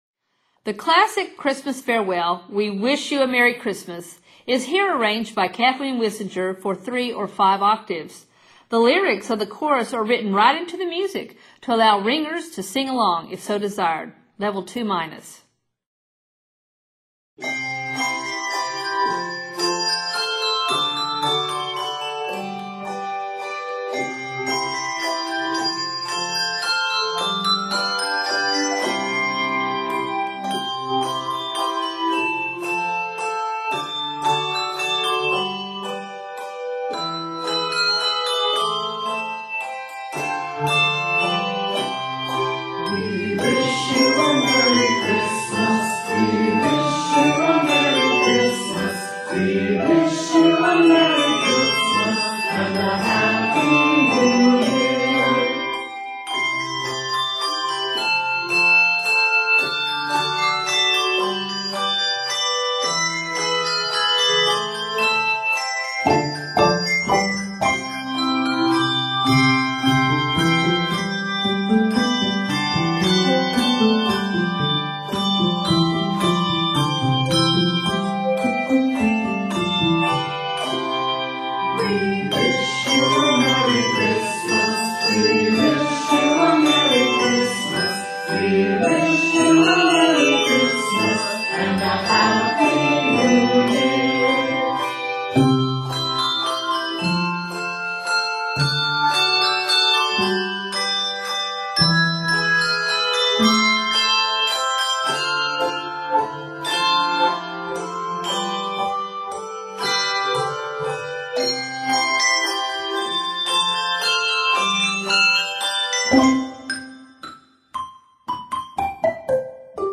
Scored in C Major, this piece is 65 measures.